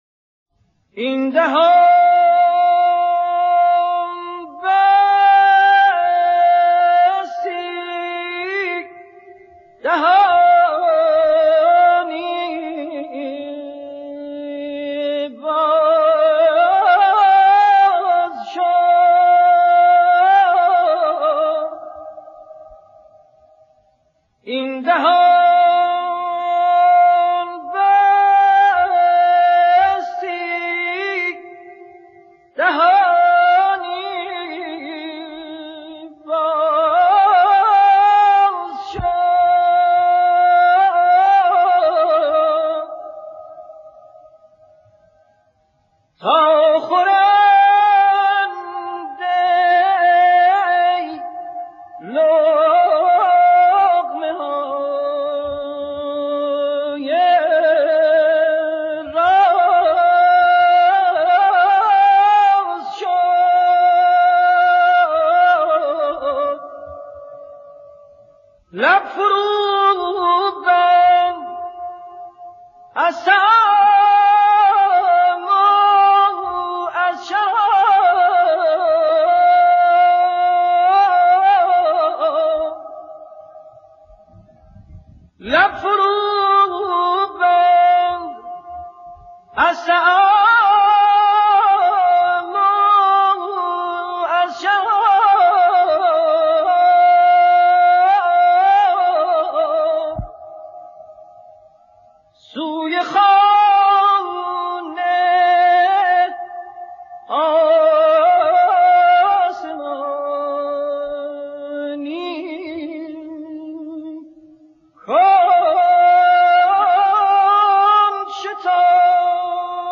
مناجات مثنوی افشاری